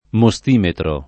[ mo S t & metro ]